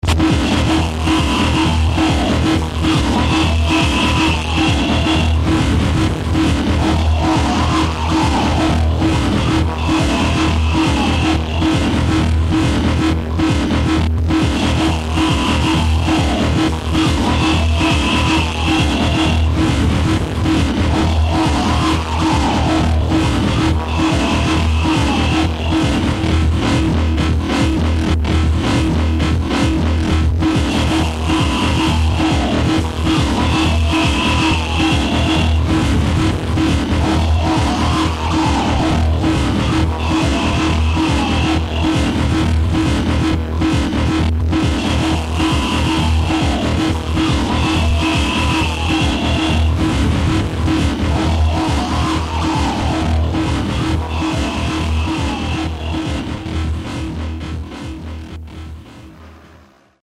File under: Industrial / Noise